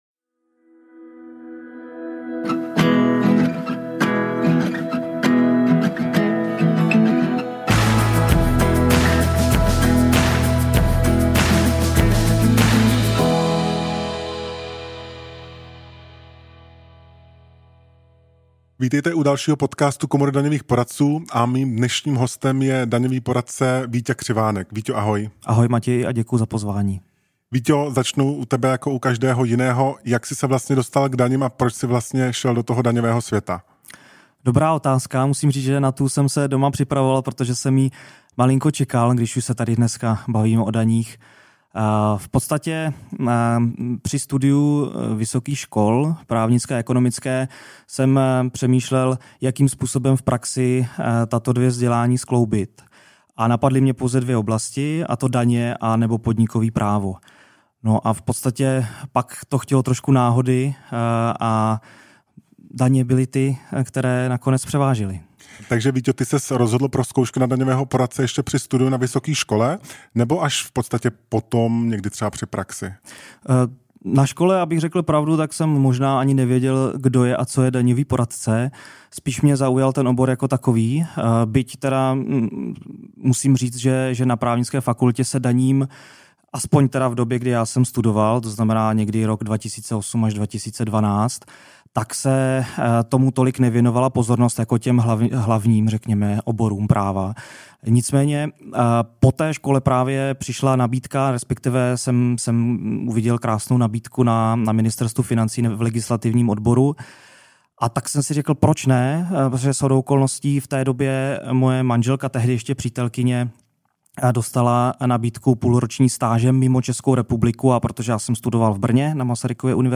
Porovnání těchto různých pohledů mu dává nebývalý nadhled a hluboké pochopení profese. Zveme Vás k poslechu rozhovoru